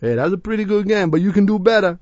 l_youcandobetta.wav